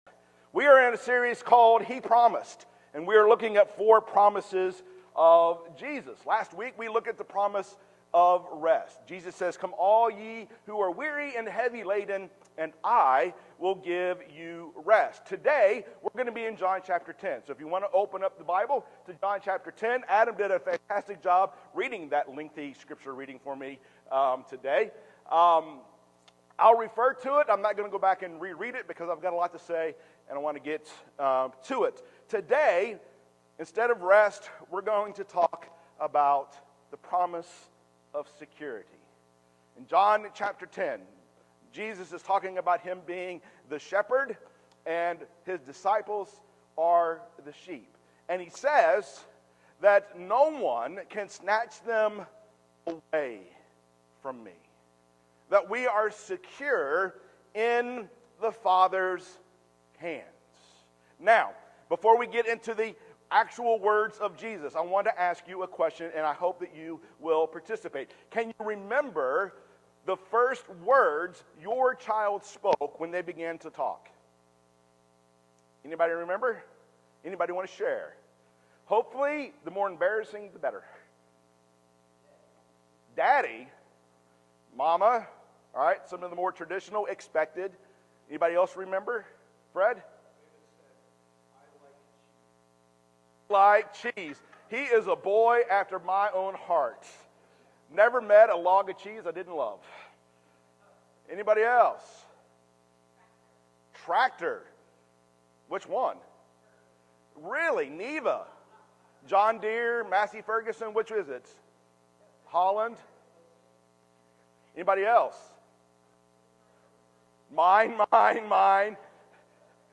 Sermons | Wheeler Road Church of Christ